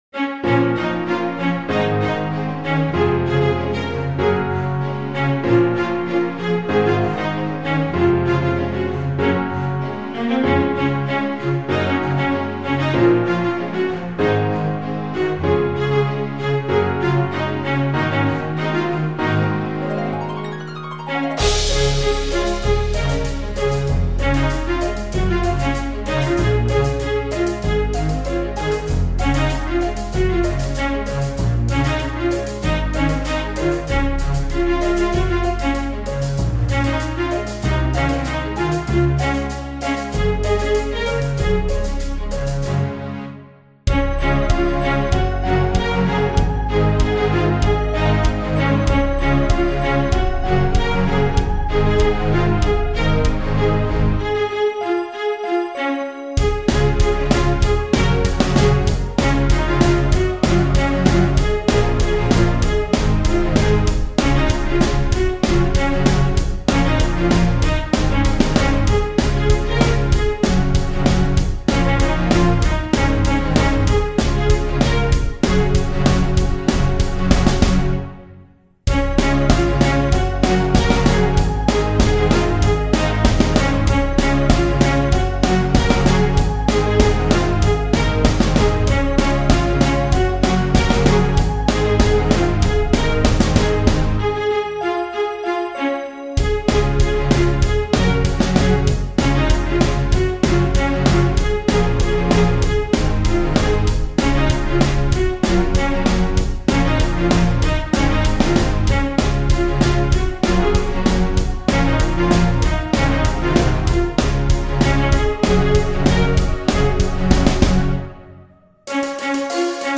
Time to make VST music!!!!
no vocals, and its mellowed out a bit